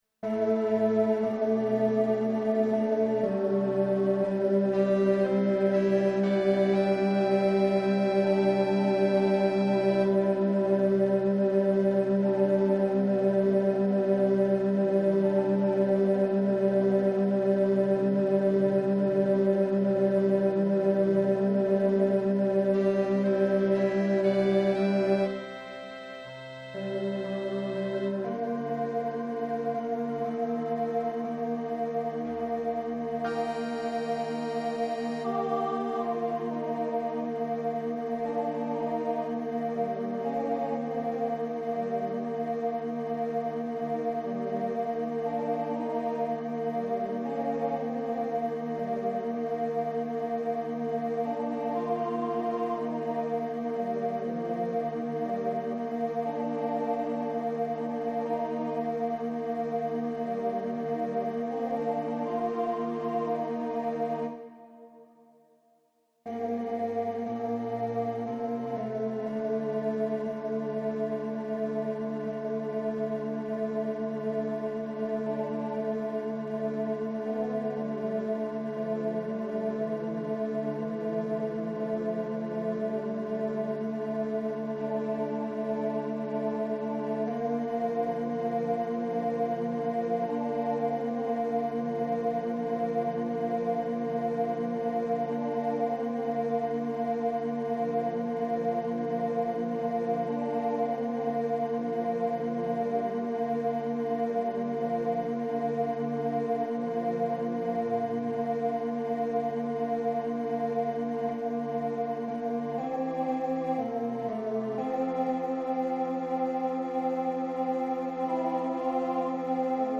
Tenor Practice Files